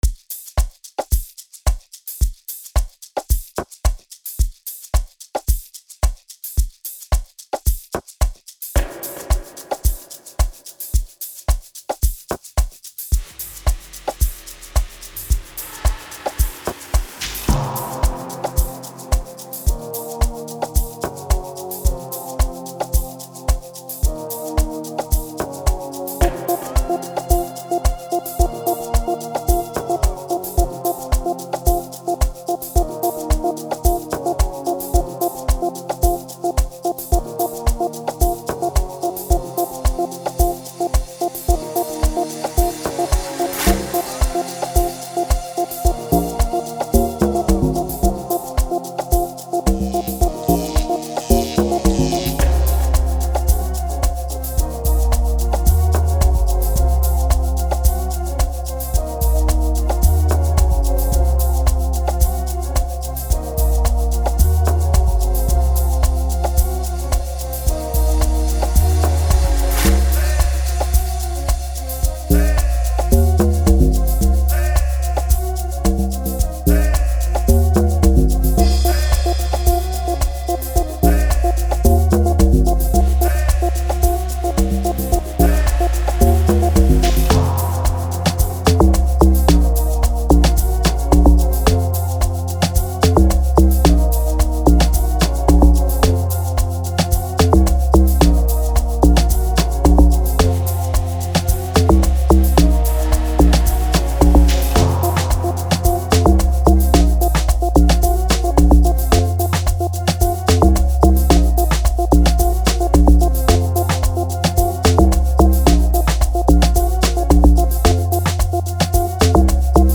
05:31 Genre : Amapiano Size